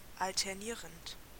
Ääntäminen
Ääntäminen Tuntematon aksentti: IPA: [ʔaltɐˈniːɐ̯nt] IPA: [ʔaltɐˈniːʁənt] Haettu sana löytyi näillä lähdekielillä: saksa Kieli Käännökset englanti alternating ranska alternant Luokat Adjektiivit Verbit